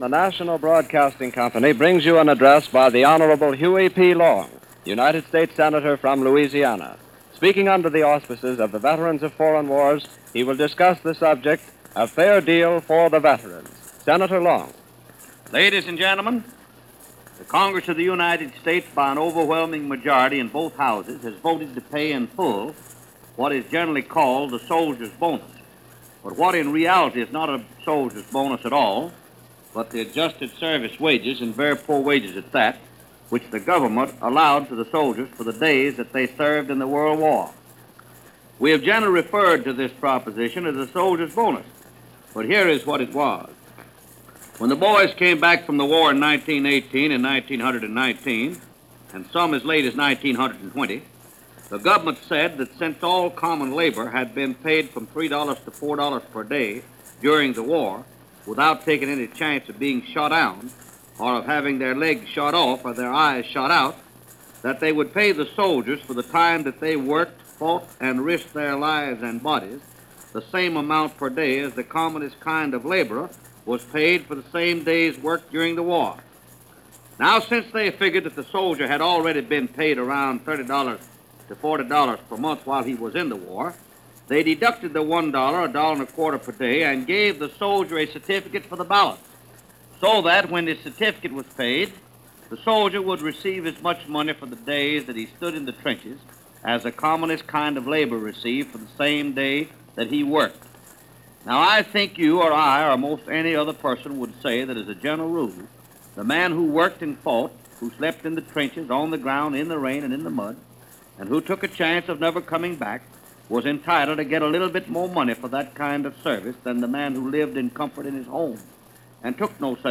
Louisiana Senator and former Governor Huey "Kingfish" Long, discusses the Veterans Bonus Bill before congress.